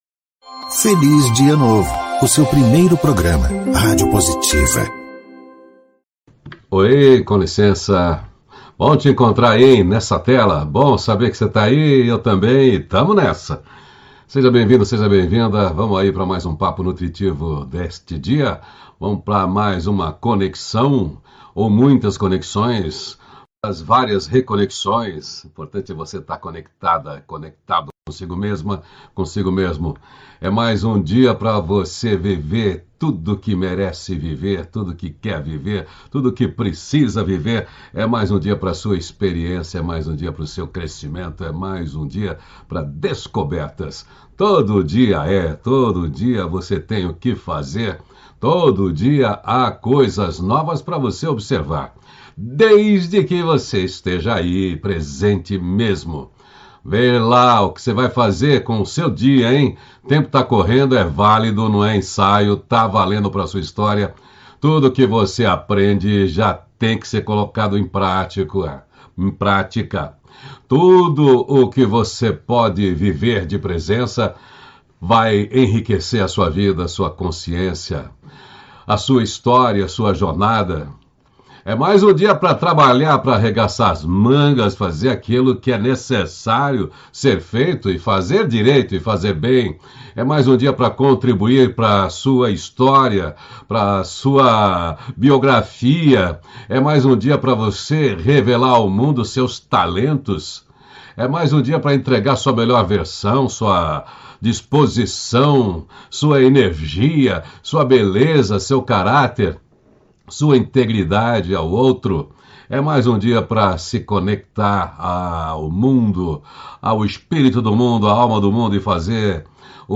-638FelizDiaNovo-Entrevista.mp3